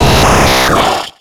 Cri d'Abra dans Pokémon X et Y.